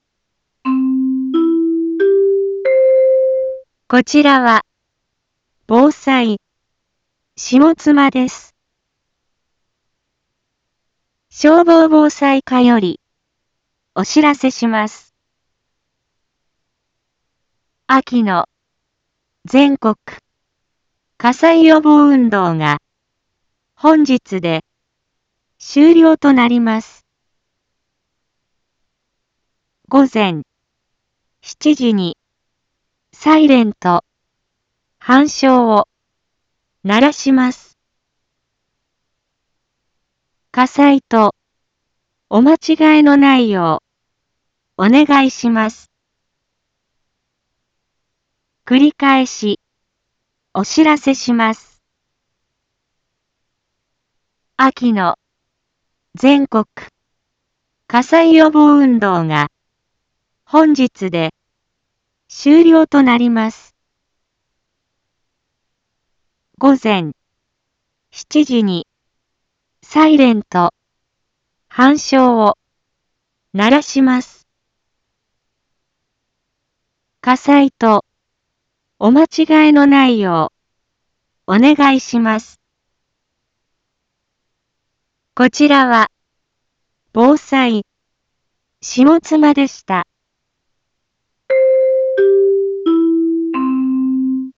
一般放送情報
Back Home 一般放送情報 音声放送 再生 一般放送情報 登録日時：2023-11-15 06:46:39 タイトル：秋季全国火災予防運動に伴うサイレン吹鳴 インフォメーション：こちらは、防災、下妻です。